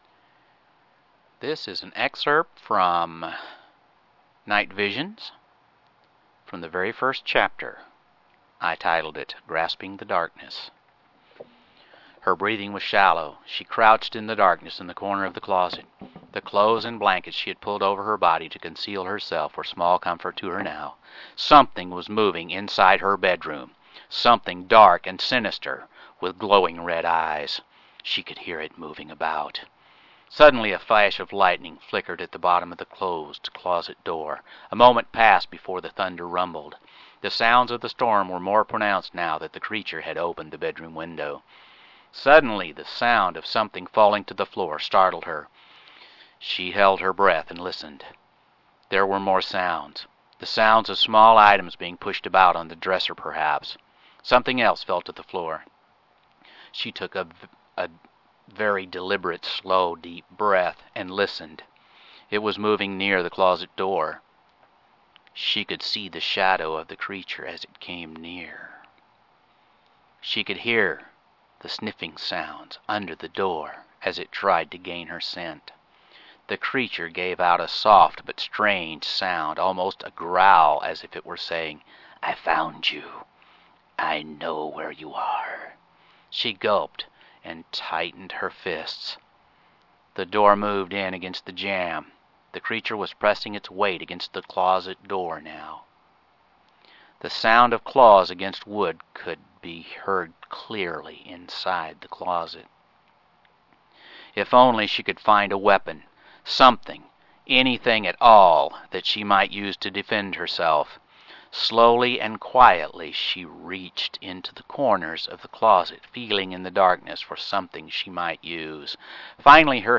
Listen to the author read this excerpt in